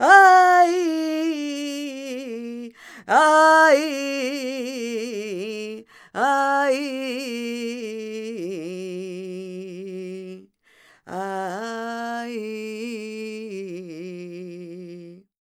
46b01voc-fm.aif